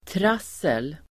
Uttal: [tr'as:el]